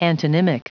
Prononciation du mot antonymic en anglais (fichier audio)
Prononciation du mot : antonymic